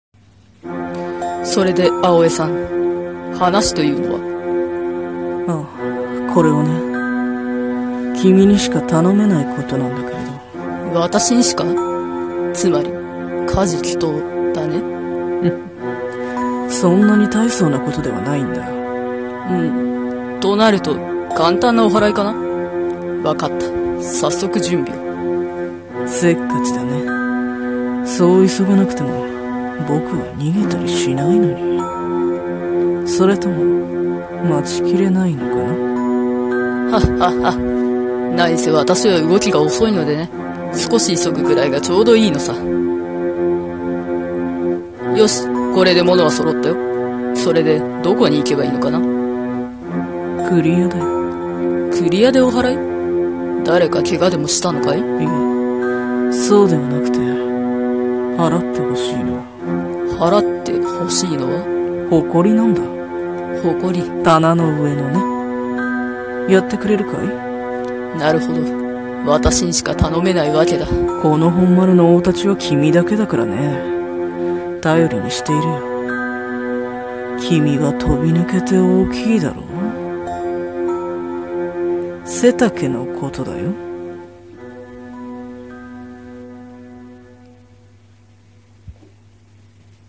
刀剣乱舞 声劇